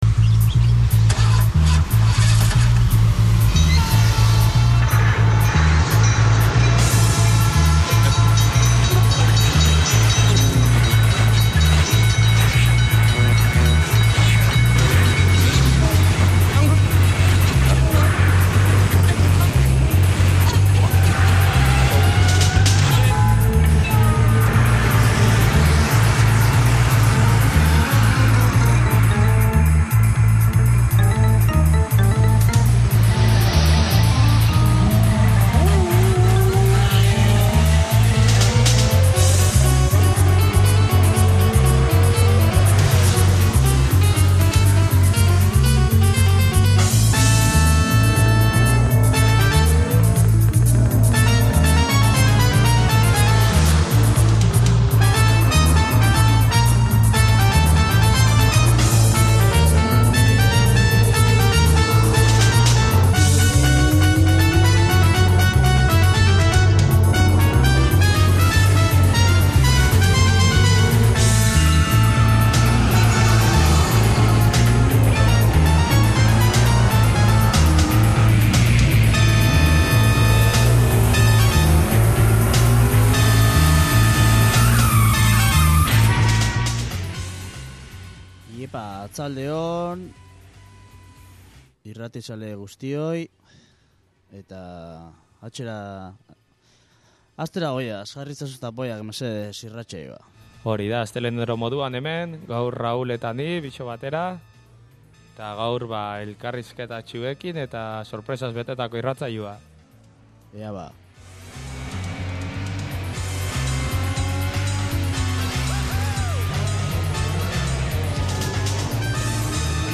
Gaur bi elkarrizketa izango ditugu gurean, hilabete bukaeran ospatzen dugun TAPOI-FEST IV-ra gonbitatu ditugun bi talde.